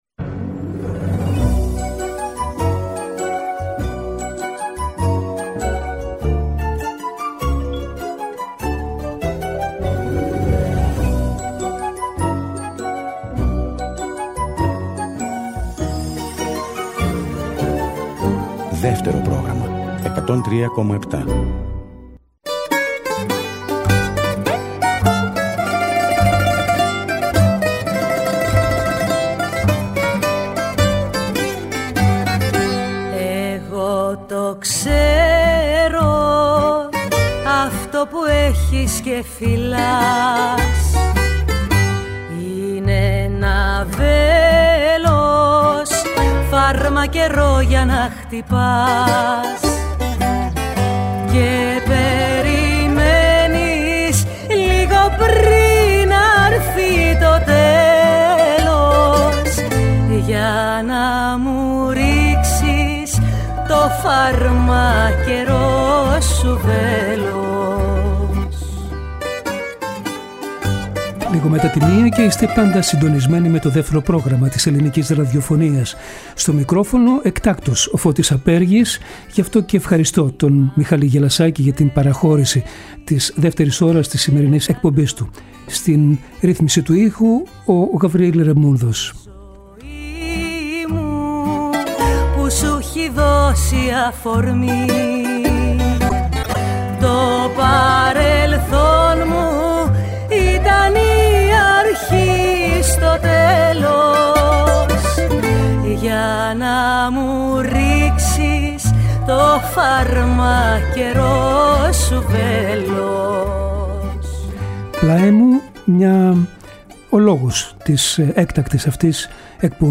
Στη διάρκεια της εκπομπής μιλά τηλεφωνικά και η Μαρία Φαραντούρη.
Συνεντεύξεις